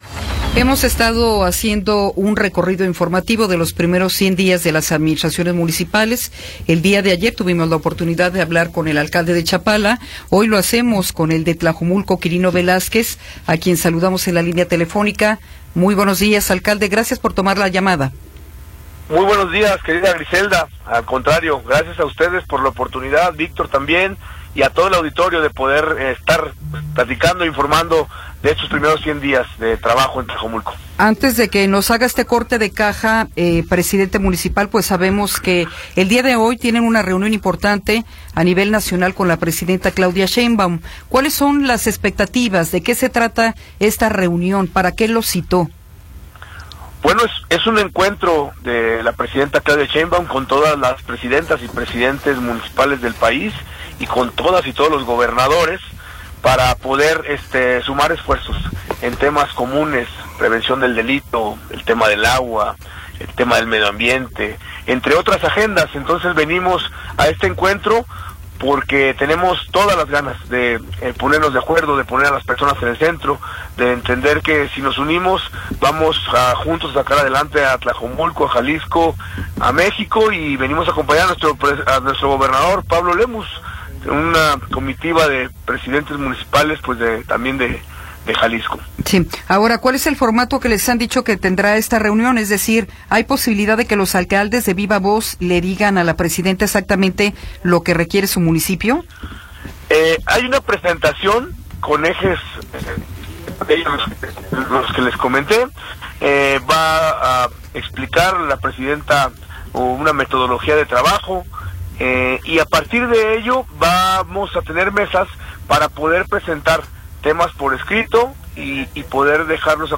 Entrevista con Gerardo Quirino Velázquez Chávez
Gerardo Quirino Velázquez Chávez, presidente municipal de Tlajomulco de Zúñiga, nos habla sobre los primeros 100 días de su administración.